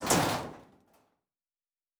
pgs/Assets/Audio/Sci-Fi Sounds/MISC/Metal Foley 1.wav at master
Metal Foley 1.wav